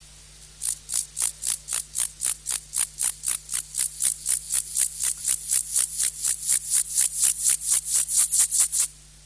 ������������ ������� ����������� Chorthippus hammarstroemi.